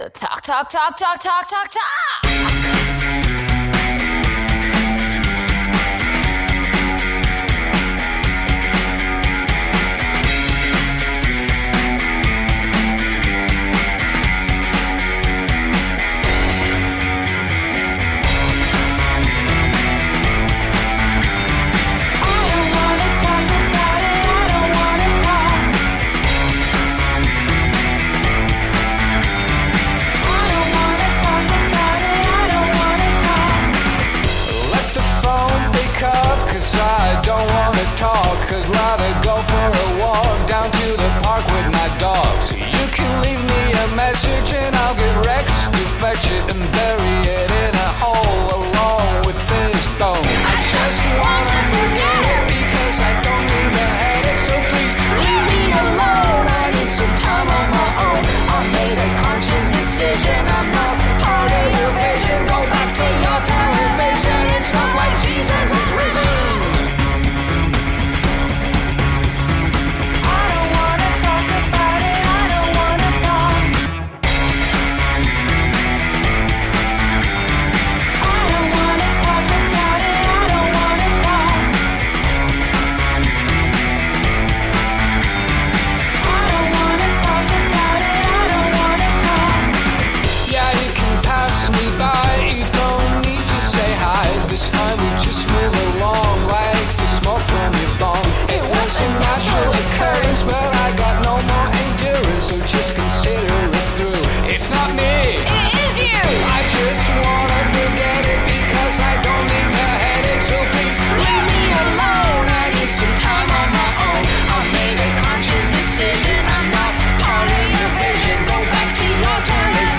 alternative/rock band
The infectious grooves and luscious melodies
five octave range voice
Some call it post modern post punk electro-acoustic hybrid.
Tagged as: Alt Rock, Pop